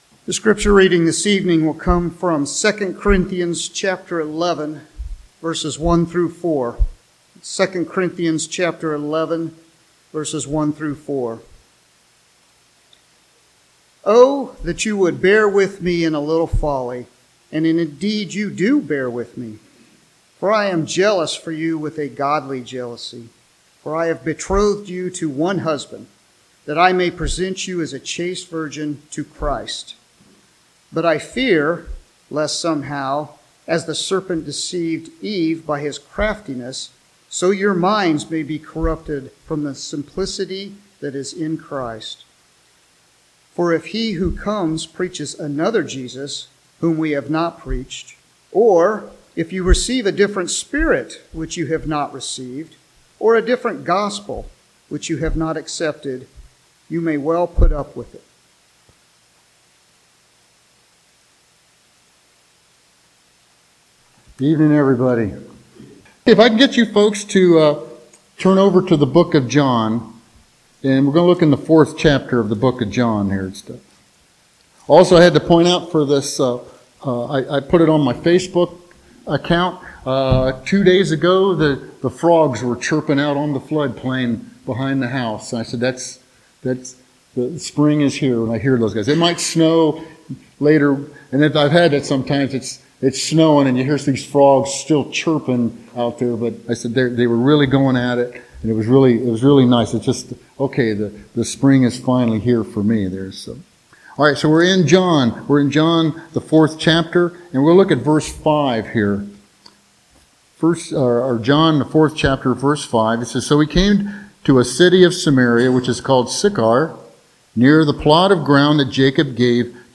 Passage: 2 Corinthians 11:1-4 Service: Sunday Evening Topics